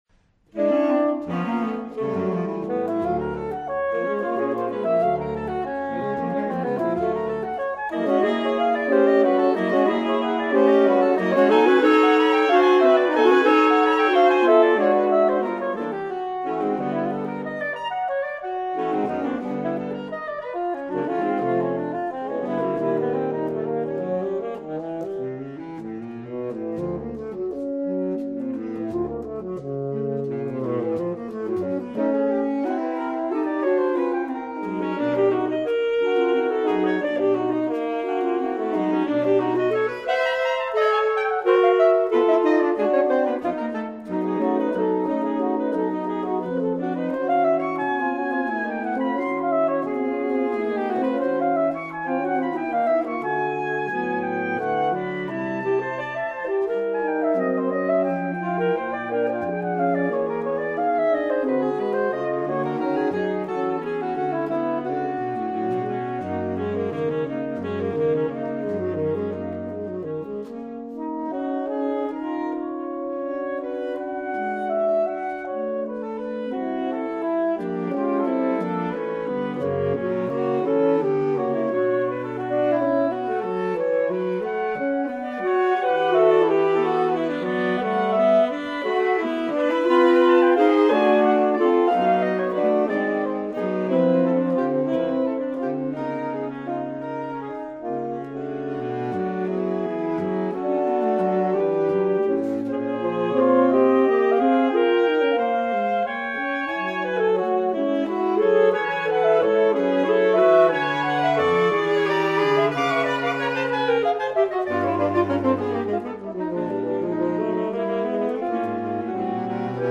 Allegro non troppo [4.7 MB]